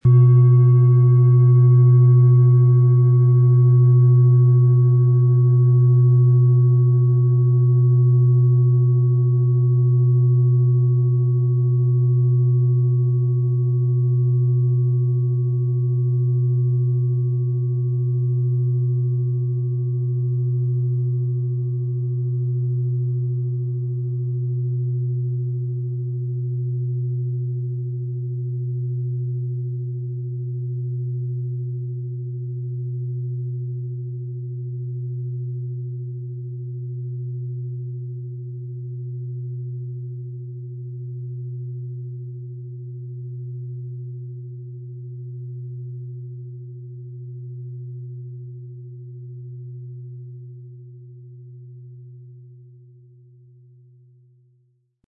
Durch die traditionsreiche Herstellung hat die Schale stattdessen diesen einmaligen Ton und das besondere, bewegende Schwingen der traditionellen Handarbeit.
PlanetentöneDelphin & Wasser
HerstellungIn Handarbeit getrieben
MaterialBronze